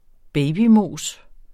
Udtale [ -ˌmoˀs ]